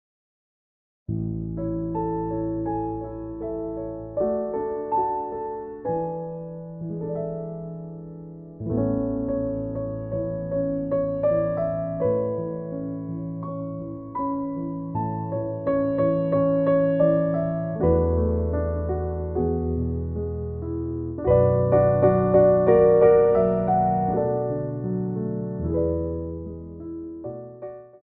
Ports de Bras 2
4/4 (8x8)